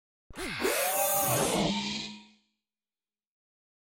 دانلود آهنگ ربات 27 از افکت صوتی اشیاء
جلوه های صوتی